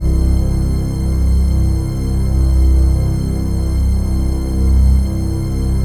DM PAD3-10.wav